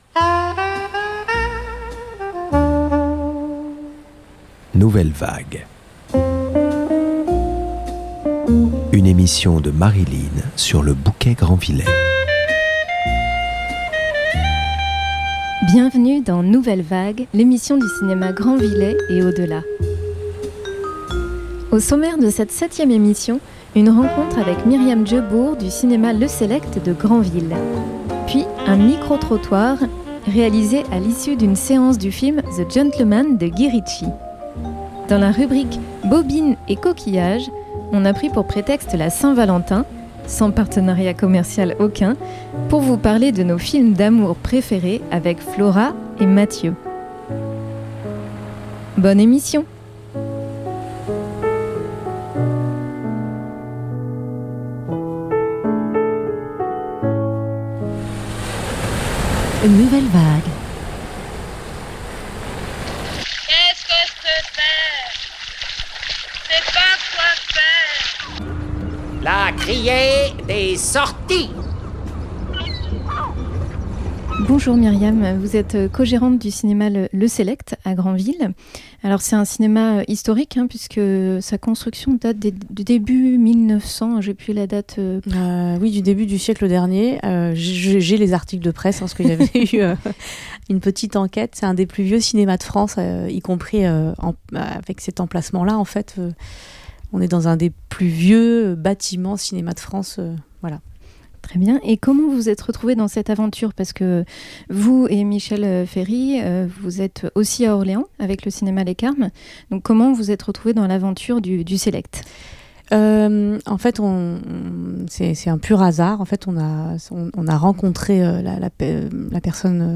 un micro-trottoir à l’issue d’une séance du film The gentlemen de Guy Ritchie
Les extraits de musiques de films qui ponctuent l’émission
animatrices web-radio